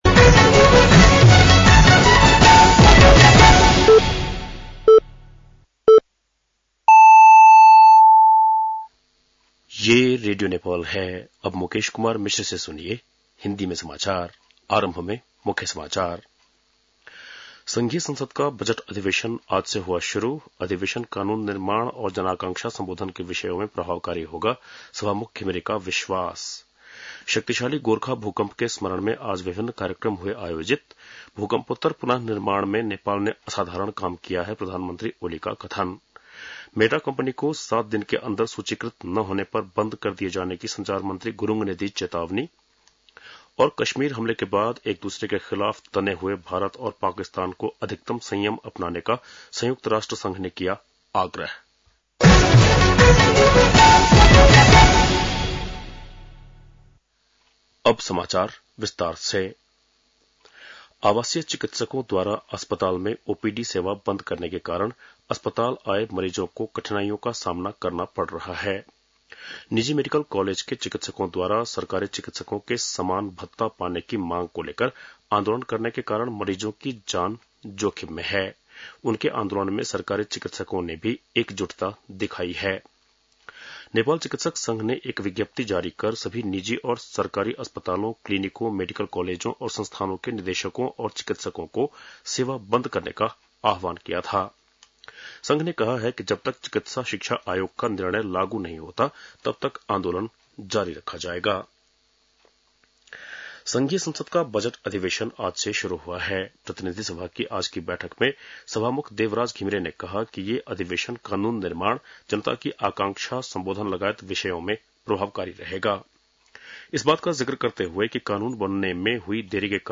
बेलुकी १० बजेको हिन्दी समाचार : १२ वैशाख , २०८२